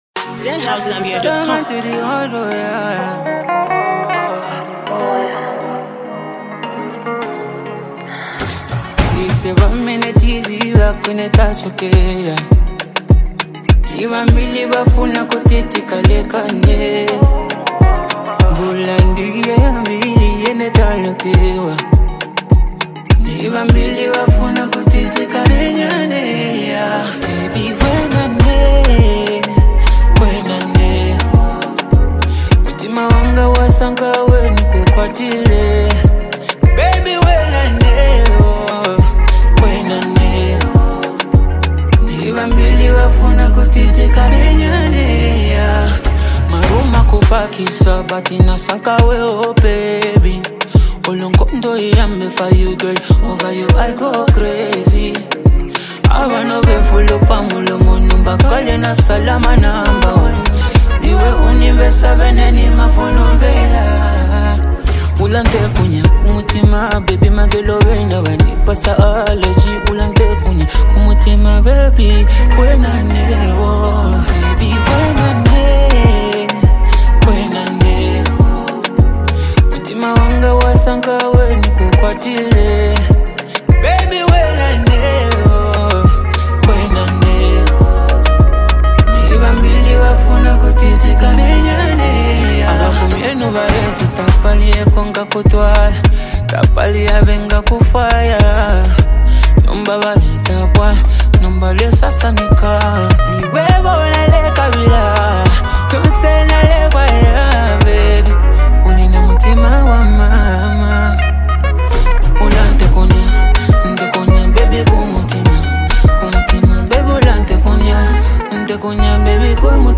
powerful vocals